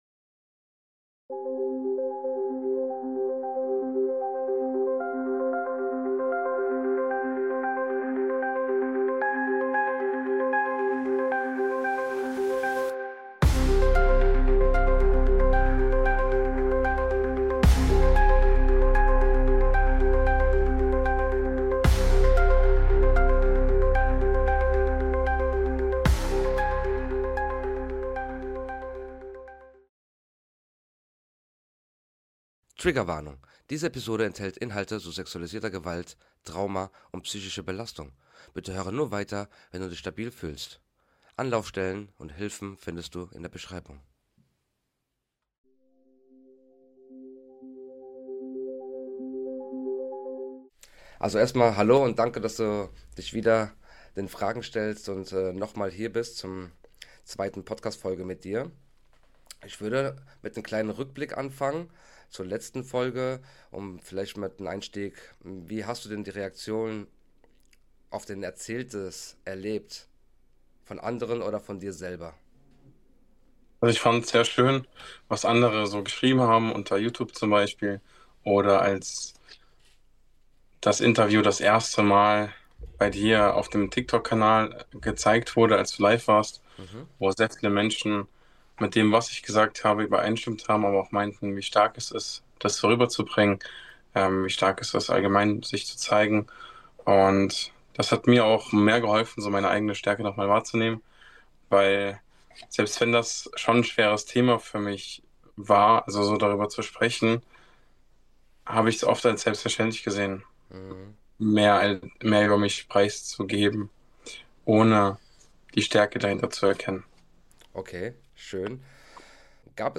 Der Weg zur Sprache – Ein Mann spricht – Teil 2 – Rückschläge, Beziehungen, Selbstheilung